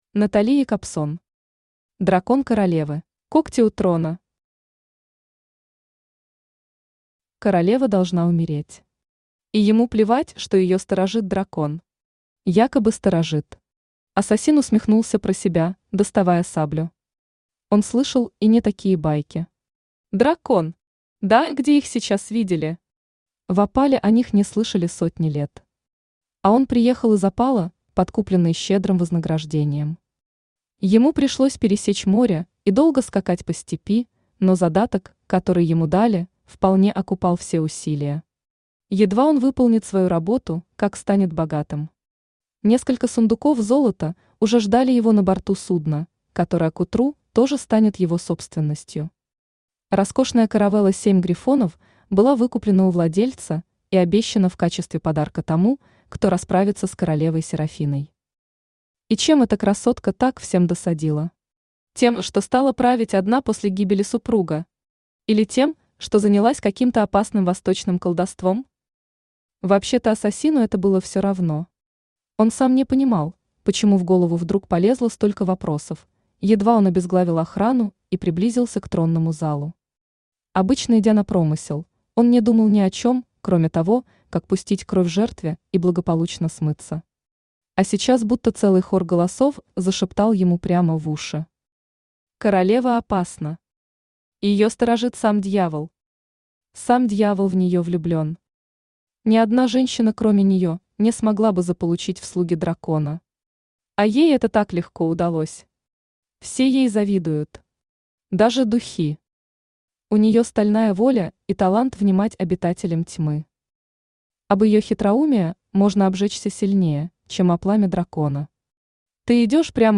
Аудиокнига Дракон королевы | Библиотека аудиокниг
Aудиокнига Дракон королевы Автор Натали Альбертовна Якобсон Читает аудиокнигу Авточтец ЛитРес.